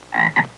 Bullfrog Sound Effect
Download a high-quality bullfrog sound effect.
bullfrog-3.mp3